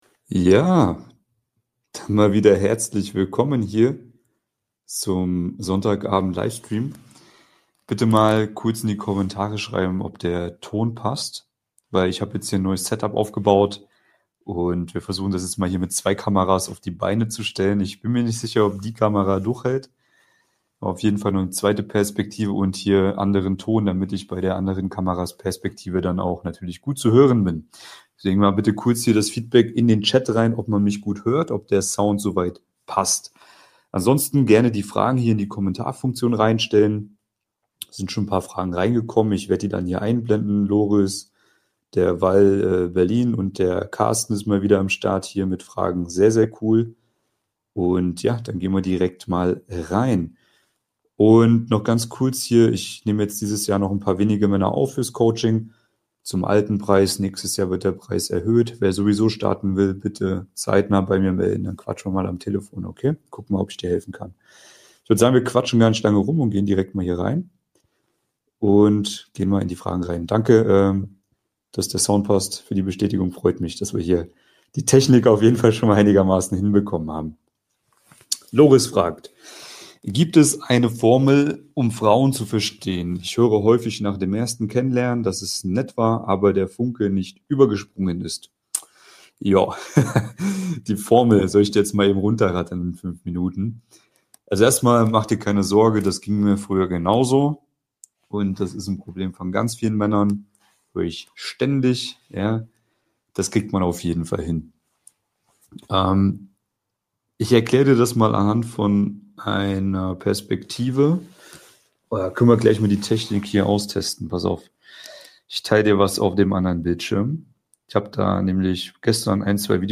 LIVE: Alles was du über Frauen und Dating wissen musst ... ~ Mission Traumfrau – Für Männer mit Anspruch Podcast
Und genau das werden wir im Livestream klären.